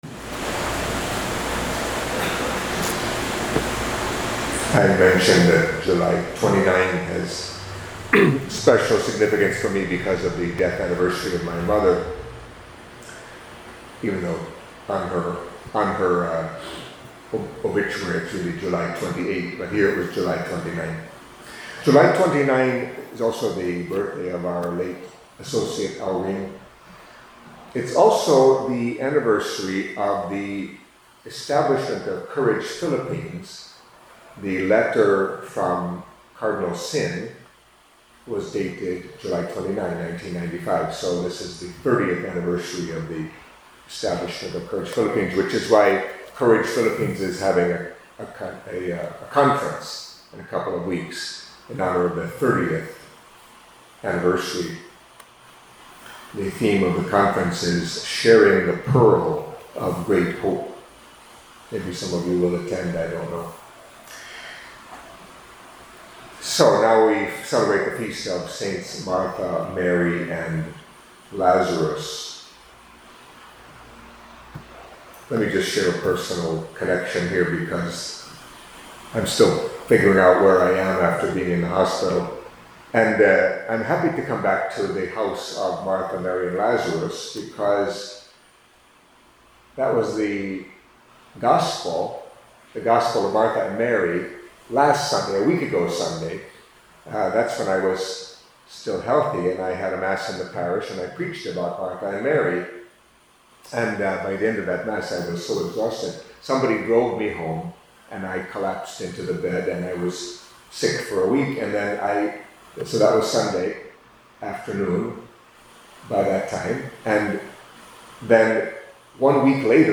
Catholic Mass homily for Tuesday of the Seventeenth Week in Ordinary Time